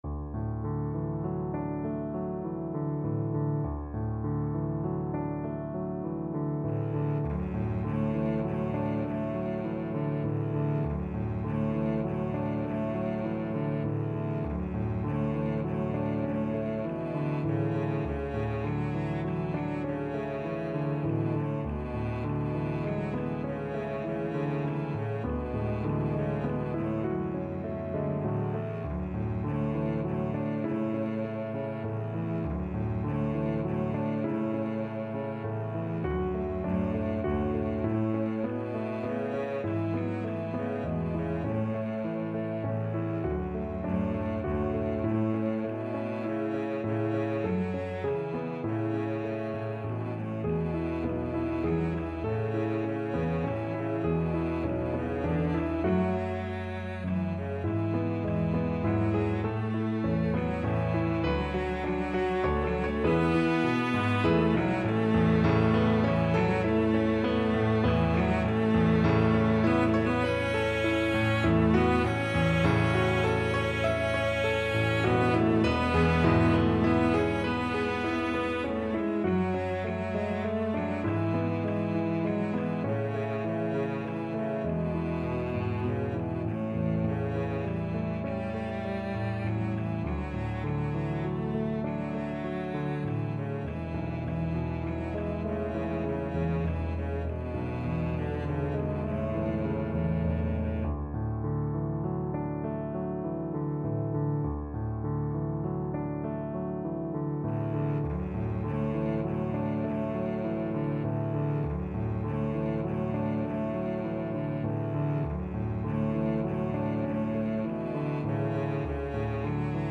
Cello
Traditional Music of unknown author.
3/4 (View more 3/4 Music)
A major (Sounding Pitch) (View more A major Music for Cello )
Gently Flowing =c.100